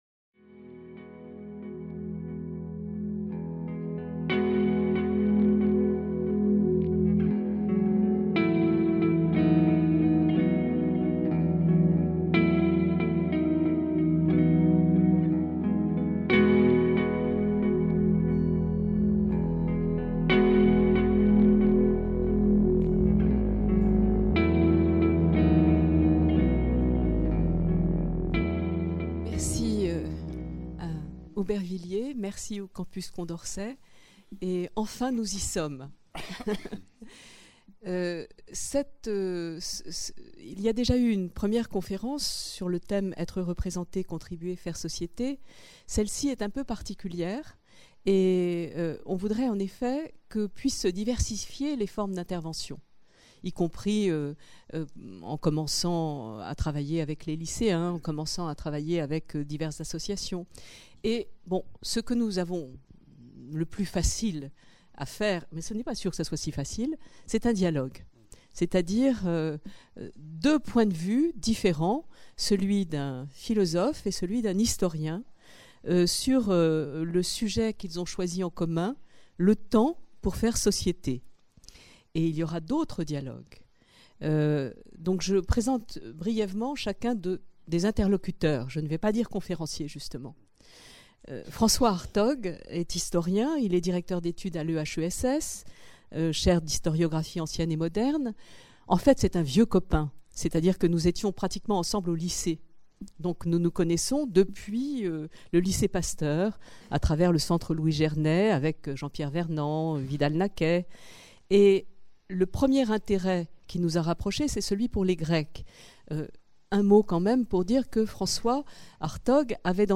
Cette conférence prend la forme d’un dialogue entre un historien, François Hartog, et un philosophe, Frédéric Worms, sur la spécificité de la situation contemporaine, à travers ses représentations du temps, chaque conférencier rencontrant les questions de l’autre : quel est notre « régime d’historicité » ?